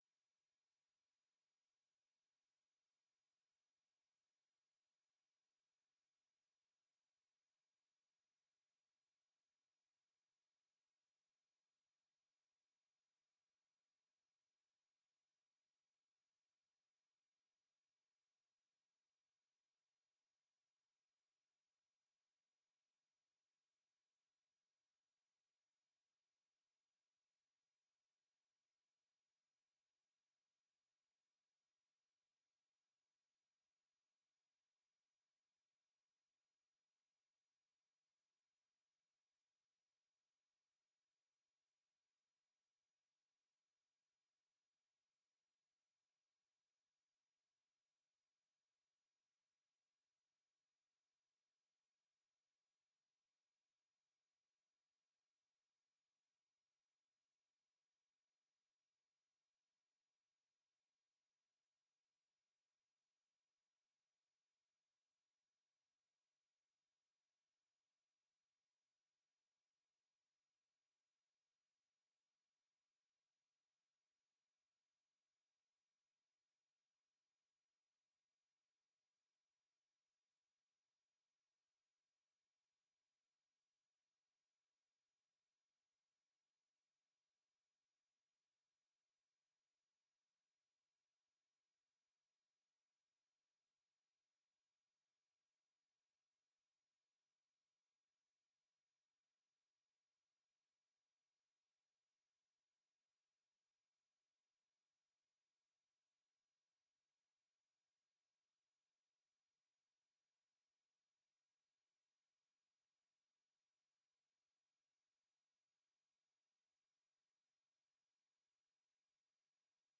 informatieve raadsbijeenkomst 18 januari 2024 19:30:00, Gemeente Doetinchem
Wethouder Huizinga trapt af met een introductie van diversiteit en inclusie. Hoe is het beleid tot stand gekomen via het plan van aanpak diversiteitsbeleid uit 2021 en de daaropvolgende uitvoeringsagenda.